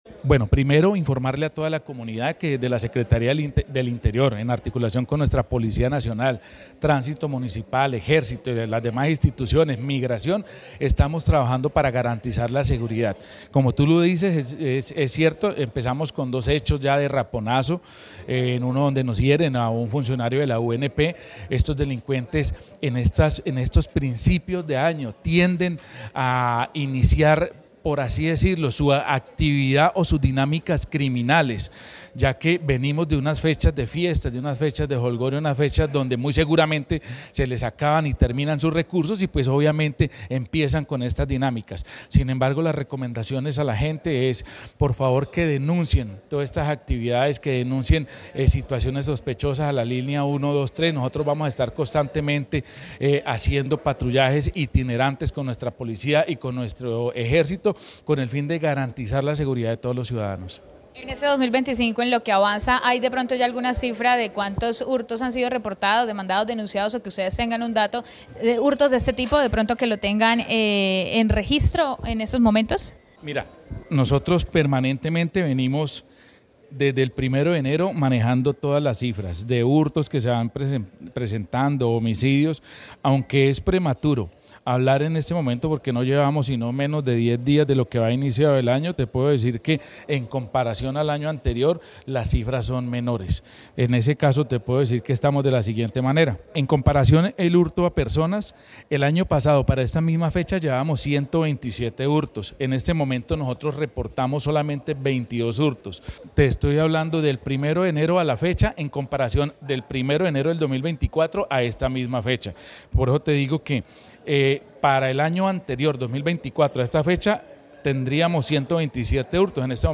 VOZ SECRETARIO DEL INTERIOR